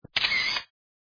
kuaimen.mp3